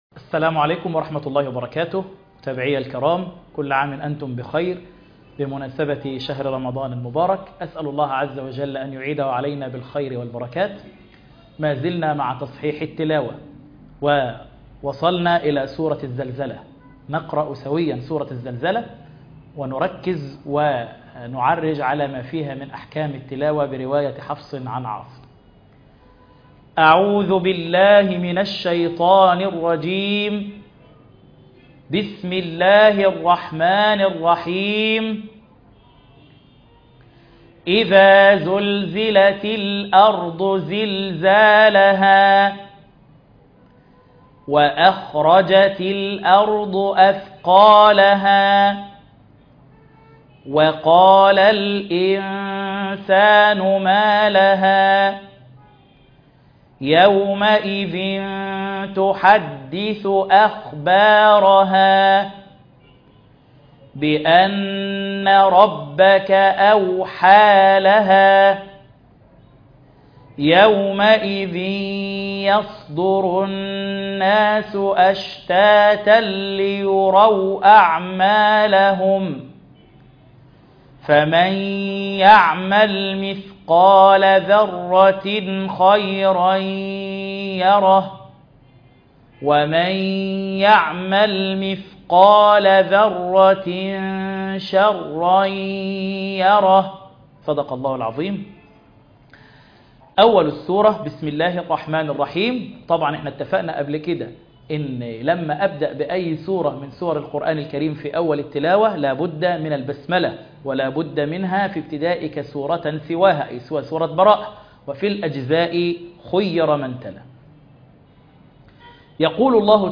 تصحيح التلاوة الحلقة